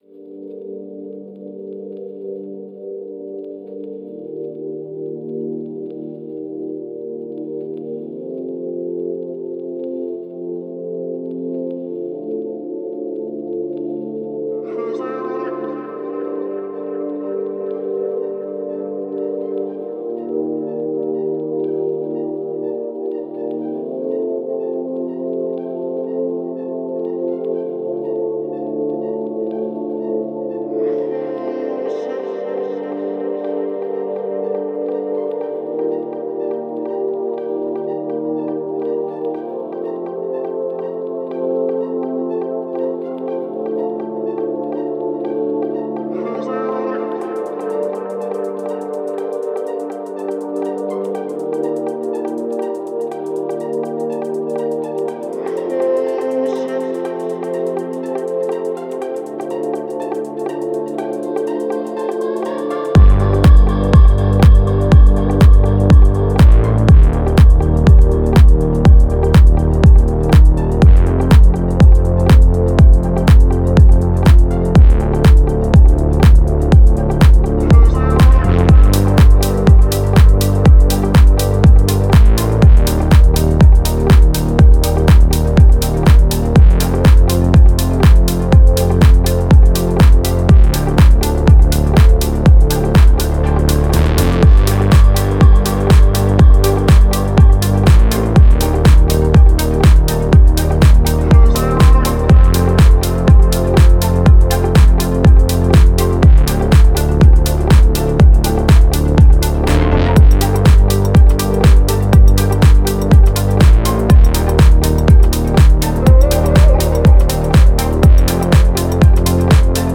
отличается мелодичными битами и запоминающимся припевом